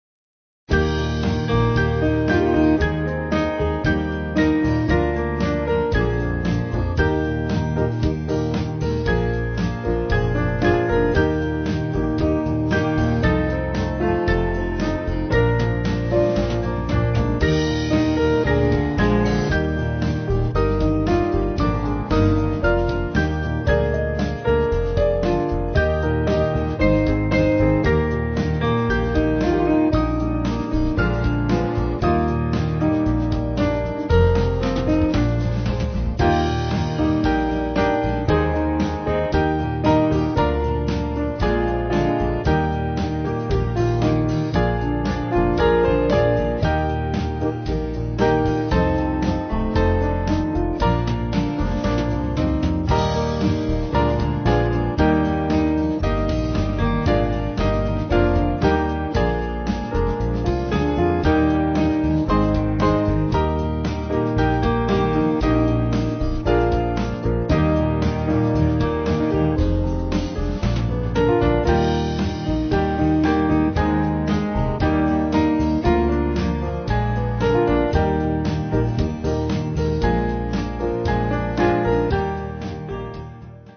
Small Band
(CM)   4/Eb-E